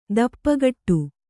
♪ dappagaṭṭu